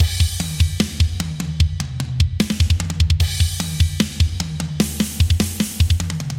描述：踢腿、小鼓和帽子
Tag: 90 bpm Hip Hop Loops Drum Loops 1.79 MB wav Key : Unknown FL Studio